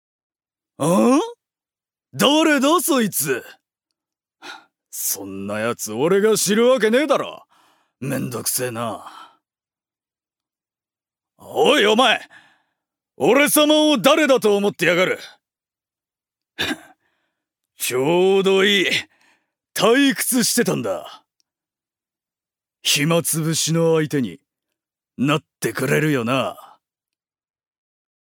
所属：男性タレント
セリフ５